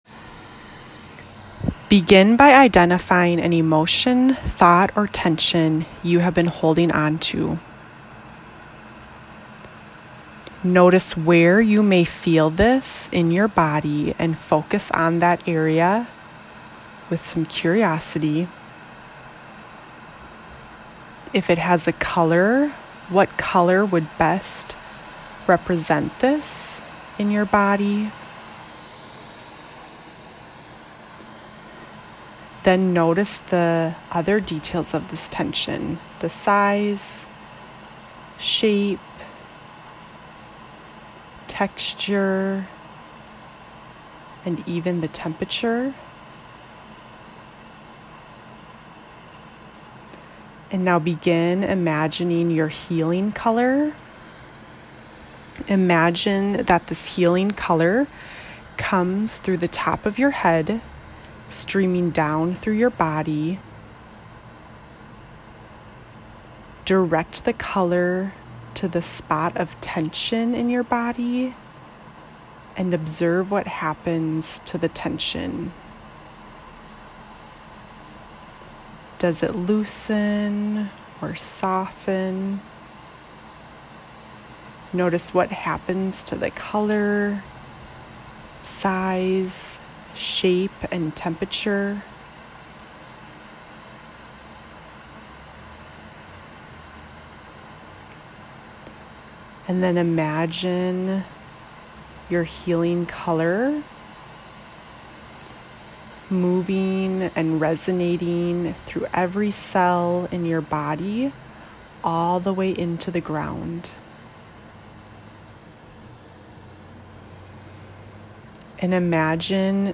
A guided meditation to observe areas of tension in the body and direct soothing color (energy) towards the tension.